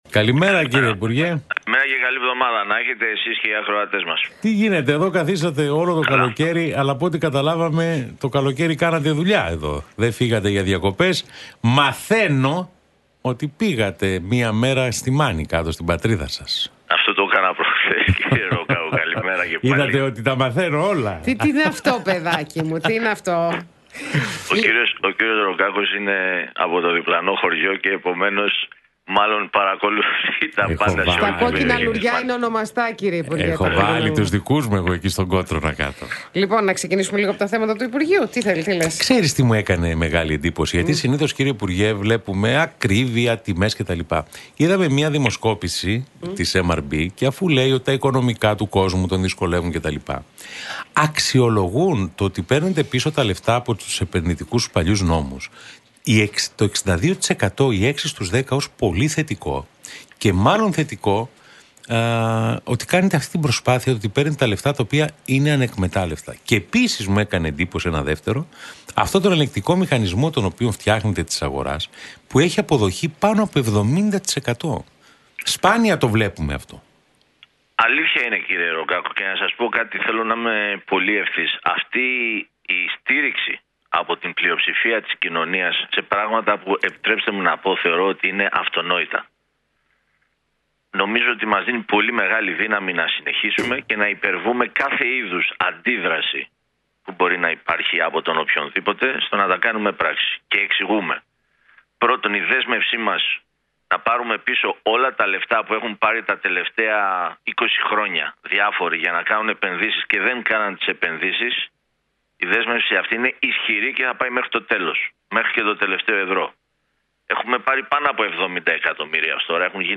μιλώντας στον Realfm 97.8